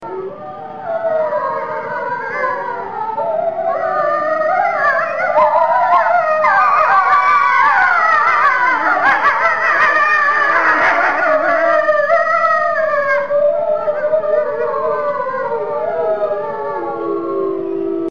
Korte naam European wolves rallying Opmerkingen A recording of the European wolves Alba, Lunca and Latea "rallying". Recorded at the UK Wolf Conservation Trust in Berkshire, England, on the 18th July 2005.
Rallying.ogg.mp3